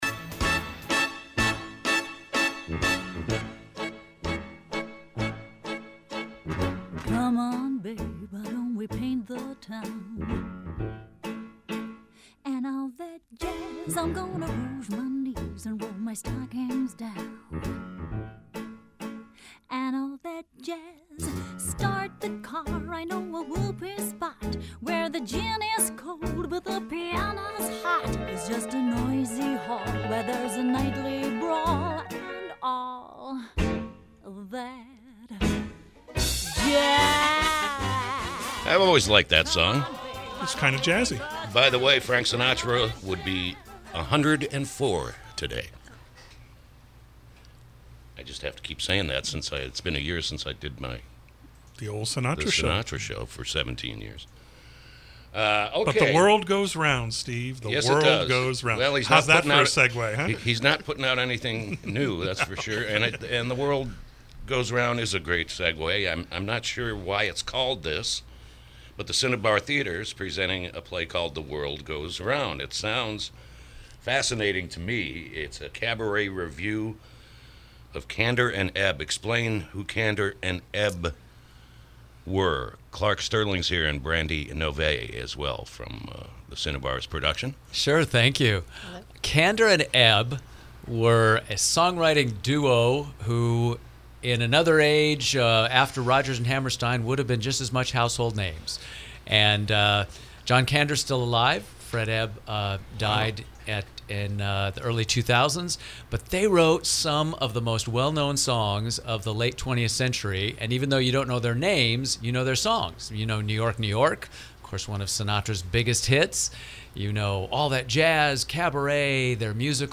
KSRO Interview: “The World Goes ‘Round”